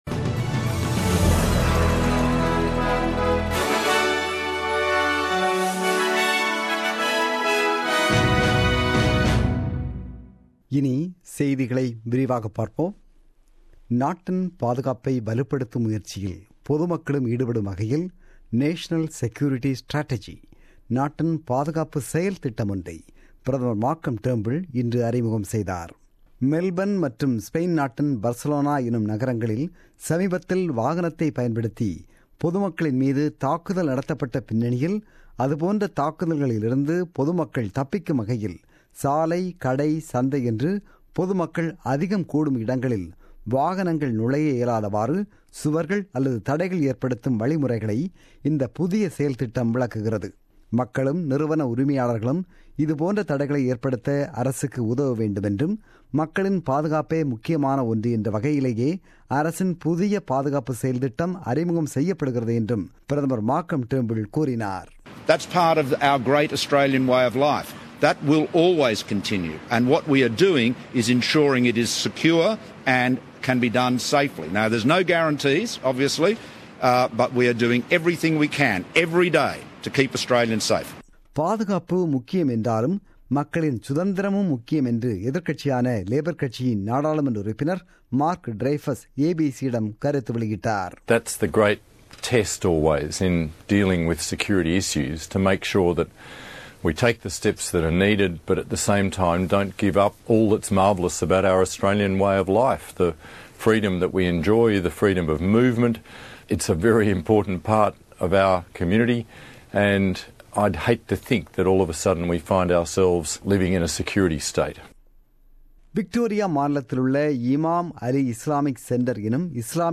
The news bulletin broadcasted on 20 August 2017 at 8pm.